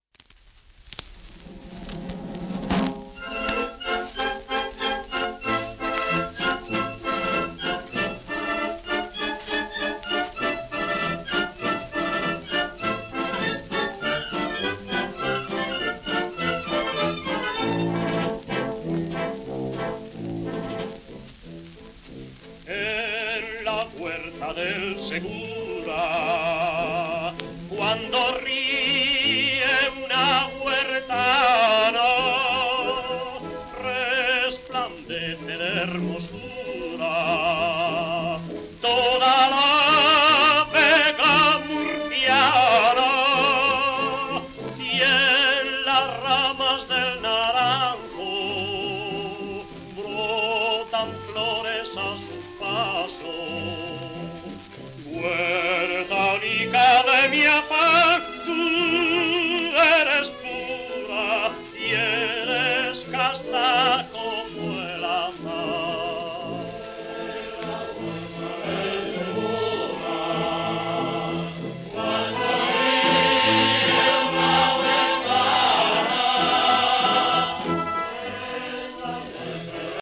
w/オーケストラ、合唱
盤質B+ *やや溝あれ有り、キズ、小キズ
1931年録音。スペインの叙情的オペラ音楽「サルスエラ」のバリトン歌手として史上最も人気のある人物